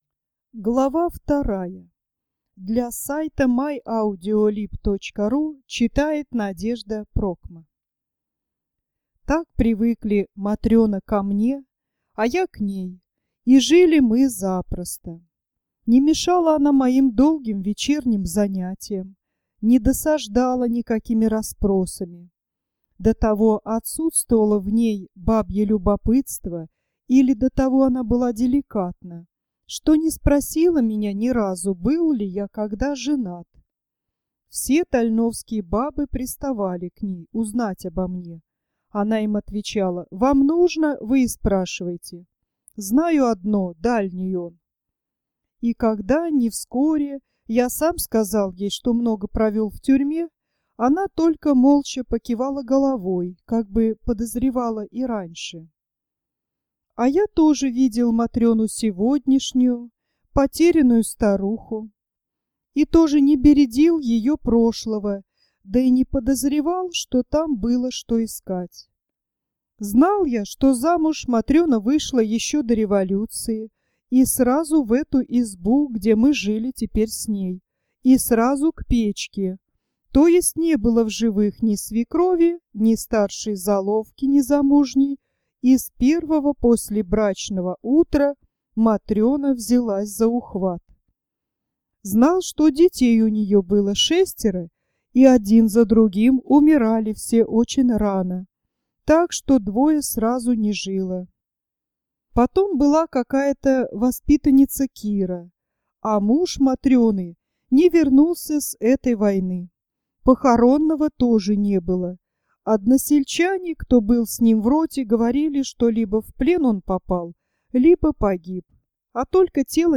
Солженицын, Матренин двор, Глава 2 31.3 MB 14+ скачать Аудио рассказ А. И. Солженицына "Матренин двор".